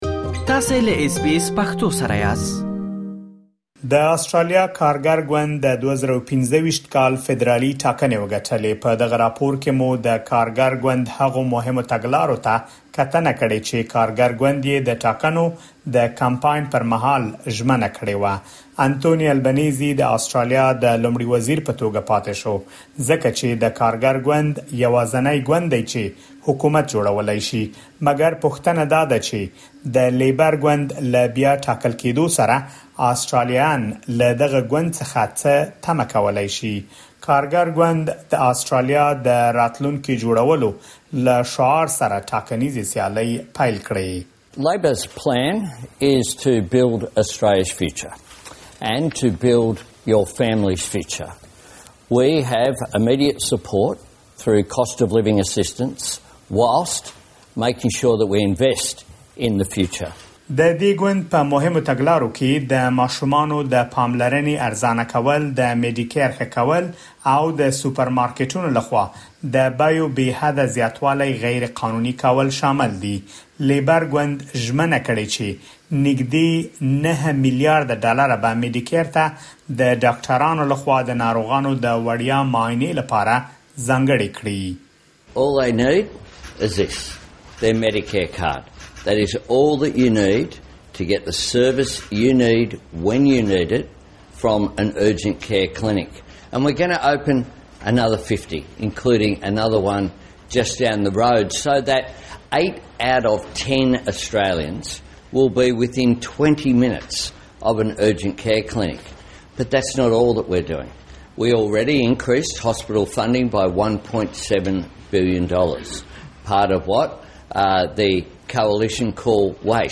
په دغه راپور کې مو د کارګر ګوند هغه پالیسۍ څیړلي دي چې د خپلو ټاکنیزو کمپاینونو پر مهال یې ژمنه کړې وه.